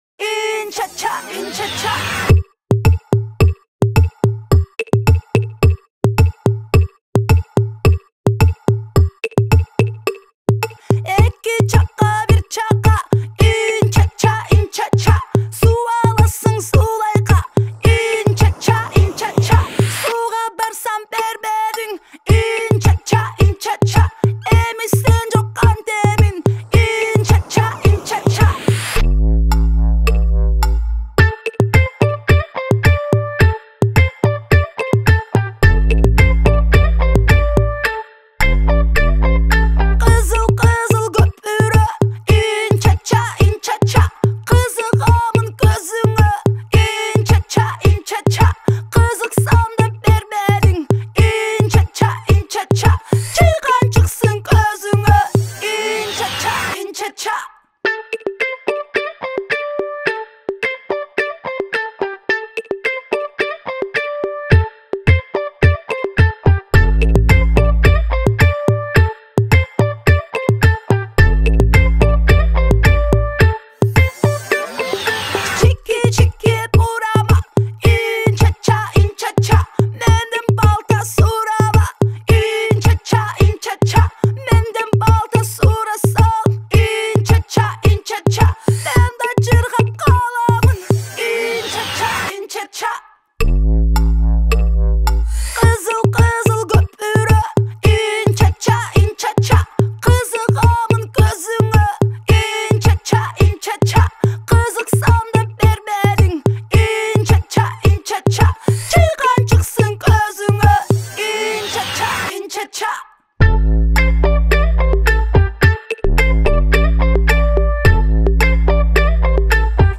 Кыргызские песни